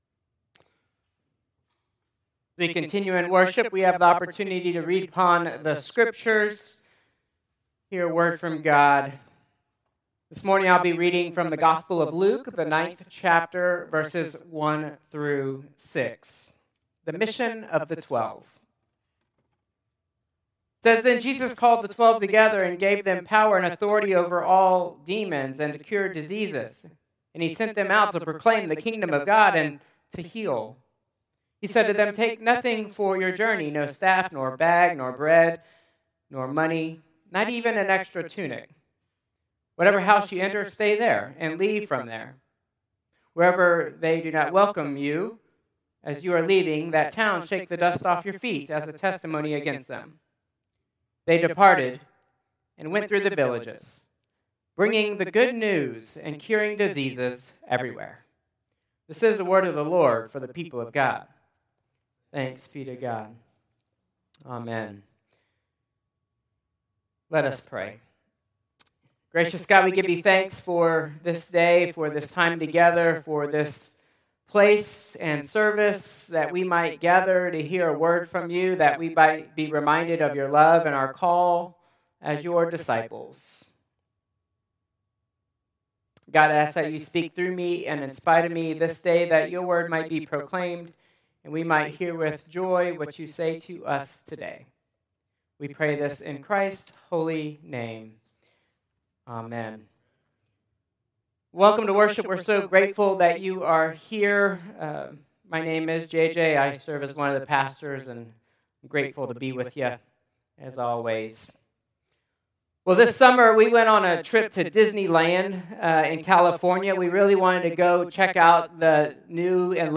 Traditional Service 11/9/2025